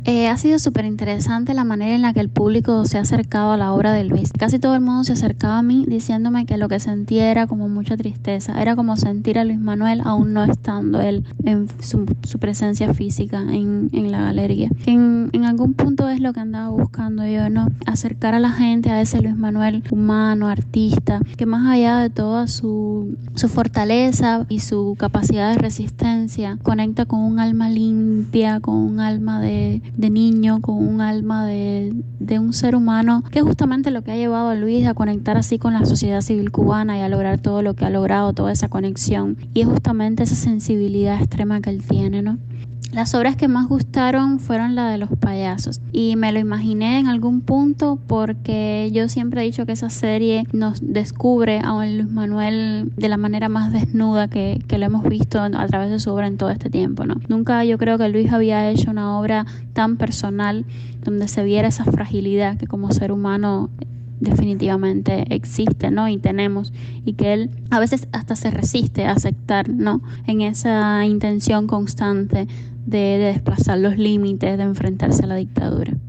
Curadora de arte habla sobre la obra de artista en prisión